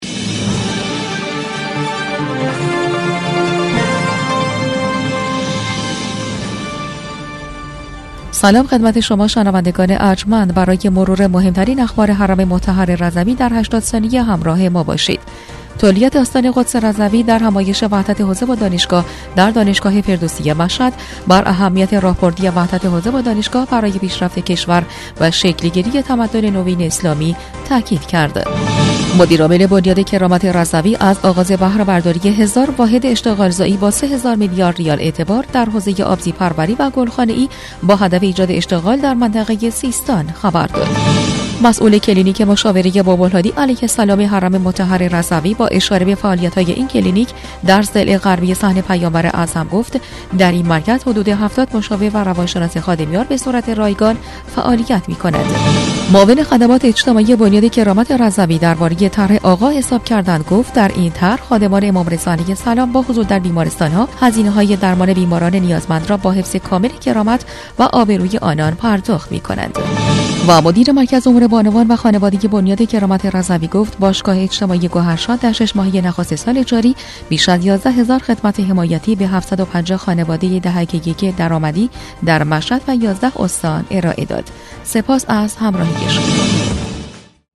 برچسب ها: آستان نیوز رادیو رضوی بسته خبری رادیو رضوی